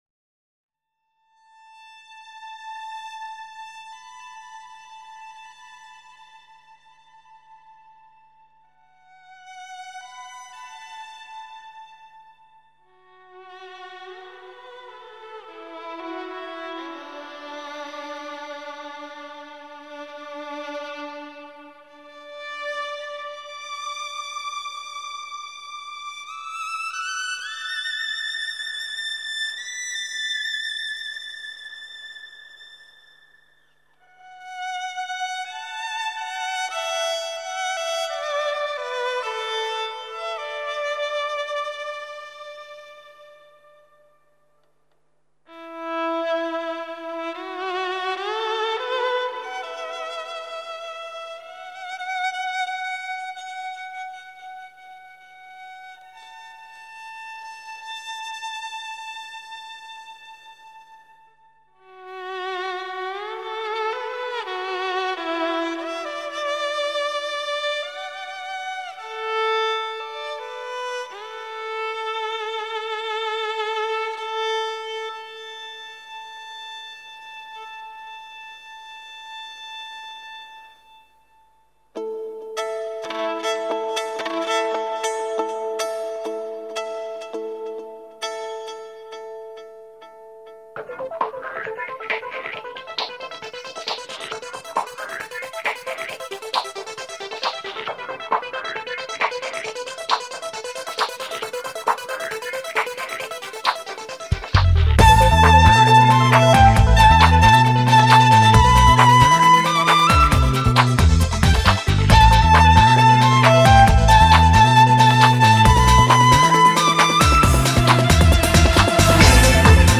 mongolska muzyka